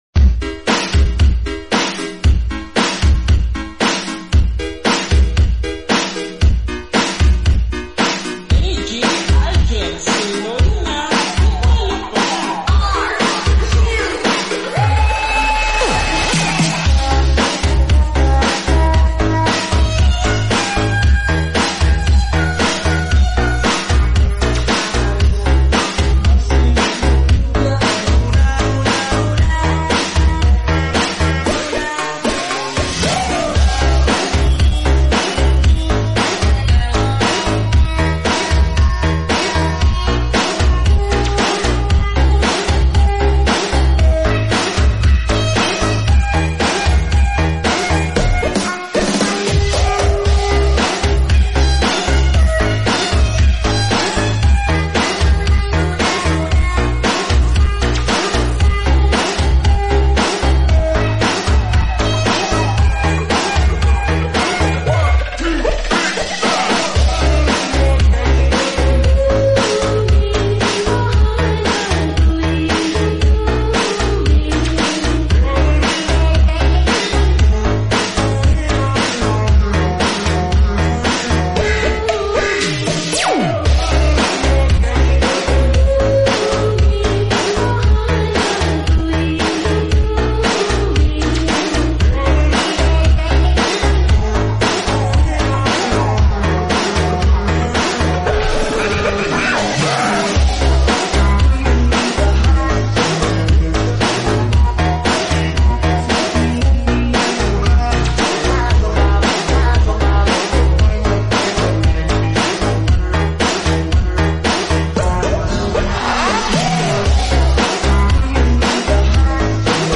TIKTOK REMIX 💃🎧FULL BASS